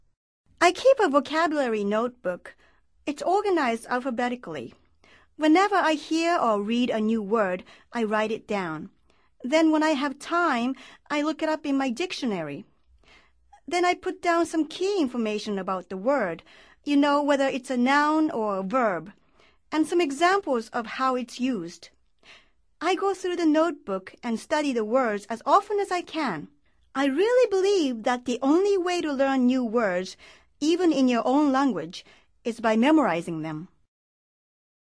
Escucha el audio y concéntrate en la entonación y ritmo de las frases.